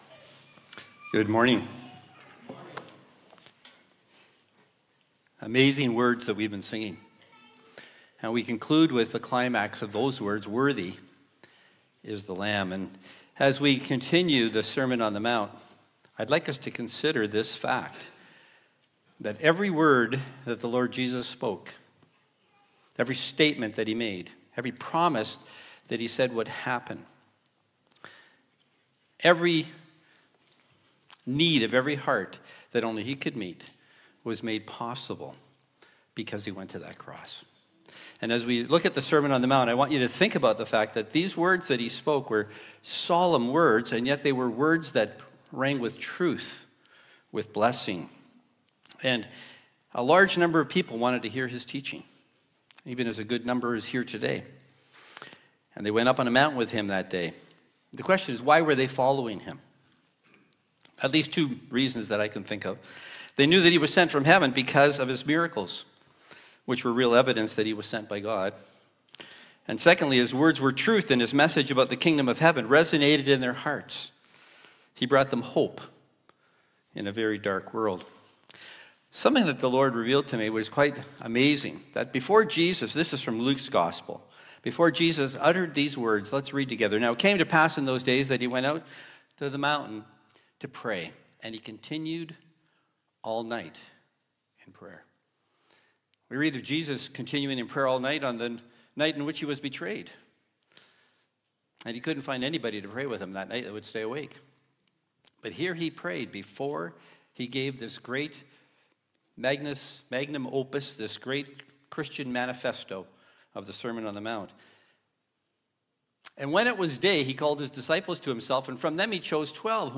Sermon on the Mount - Matthew 5:21-26